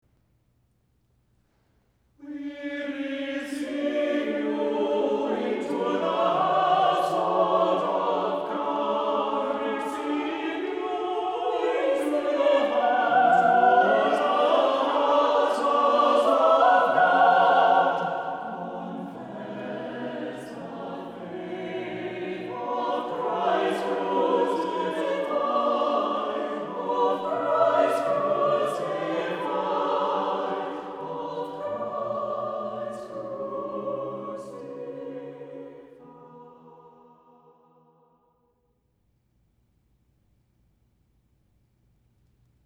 • Music Type: Choral
• Voicing: SATB
• Accompaniment: a cappella